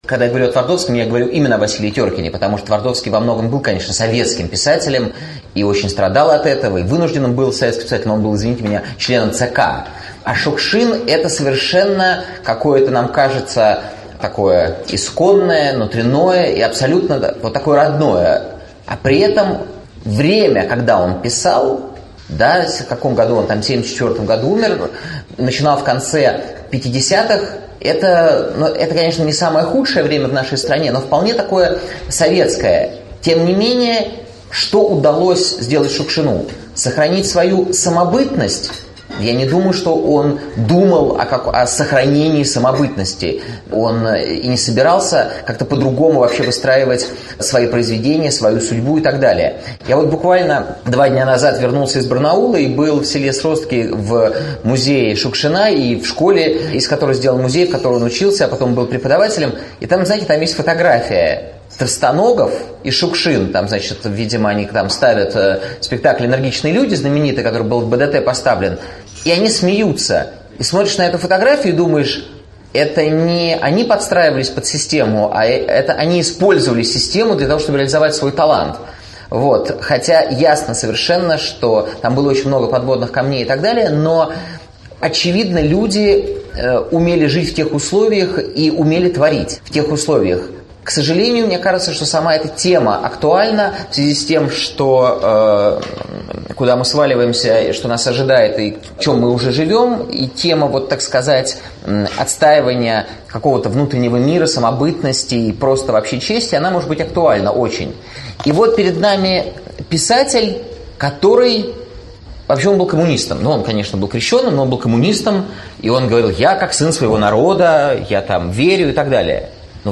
Аудиокнига Шукшин и Твардовский: как остаться русским в советское время | Библиотека аудиокниг